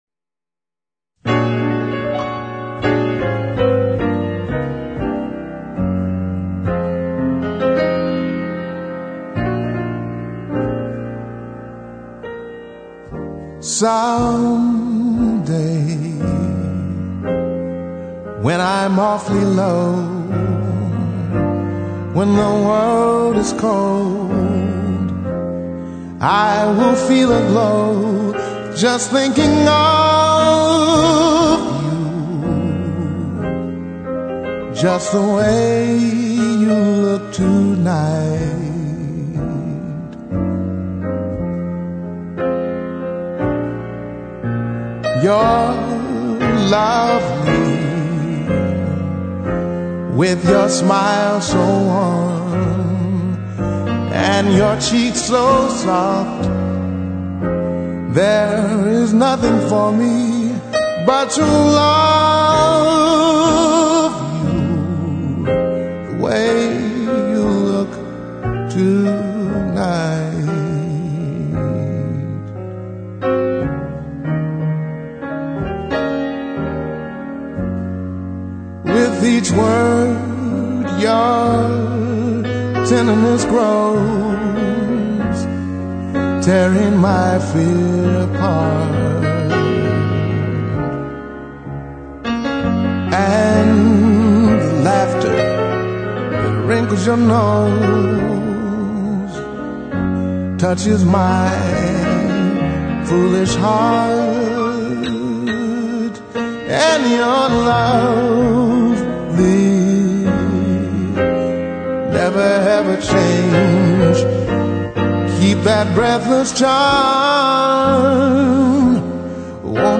jazz standards